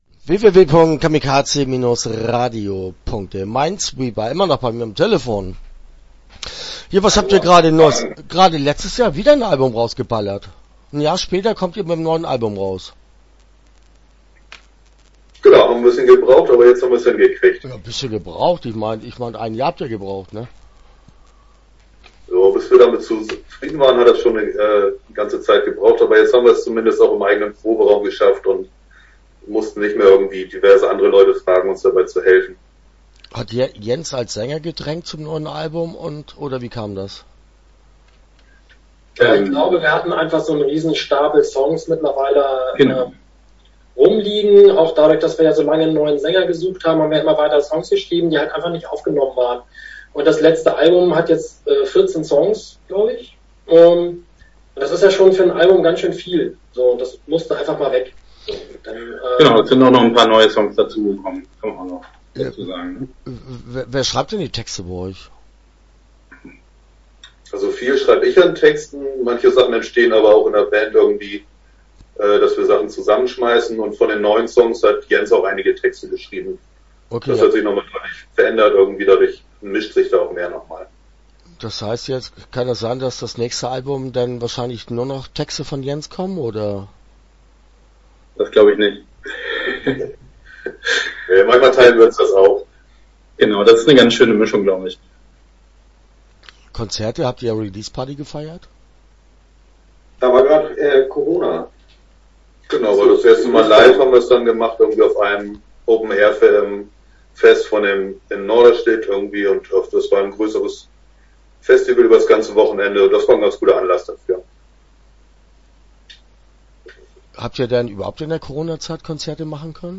Start » Interviews » Mindsweeper